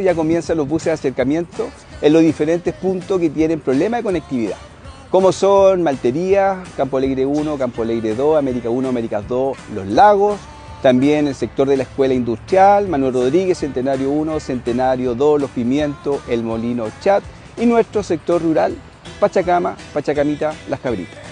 El alcalde Johnny Piraíno se refirió a nuestras emisoras, sobre el esfuerzo municipal que se está haciendo para apoyar a las personas que diariamente se deben trasladar tanto dentro como fuera de La Calera, detallando qué sectores son los beneficiados.